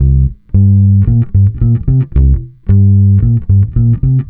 Track 15 - Bass 01.wav